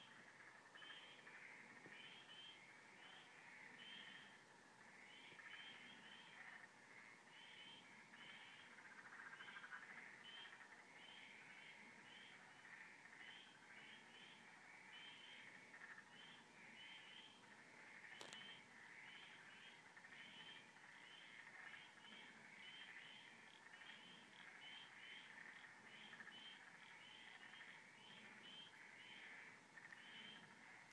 Frogs